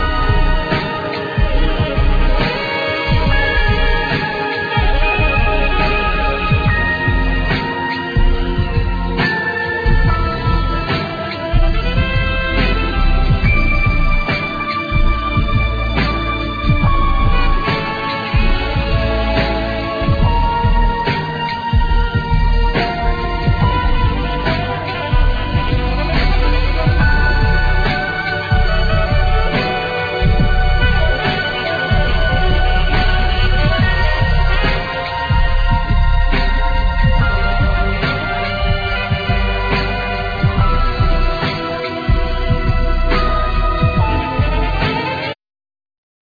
Fender Bass,Double Bass
Keyboards,Organ,Grand Piano
Saxophne
Percussion